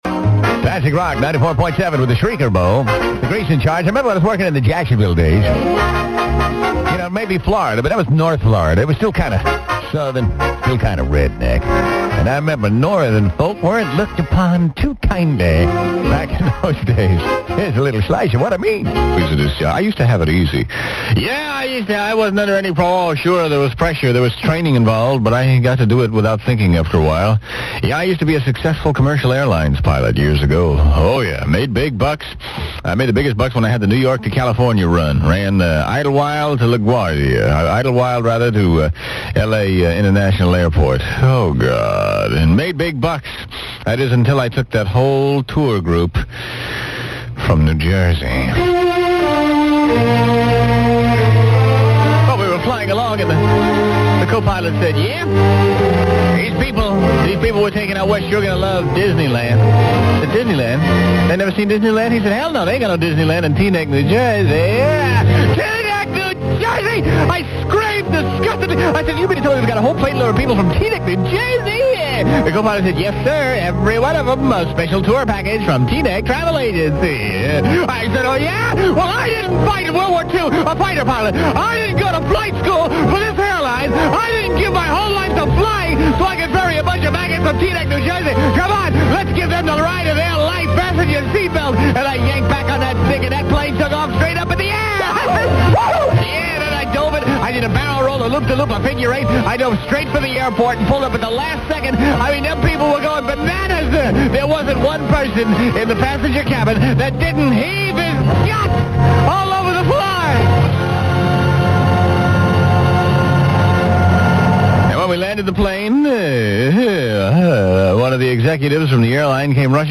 On January 30th, 1999 (the day before the Super Bowl, get it?), Classic Rock 94.7 played a collection of classic bits, including some from Grease's early days on Florida radio in the 70's for the first time in 20 years!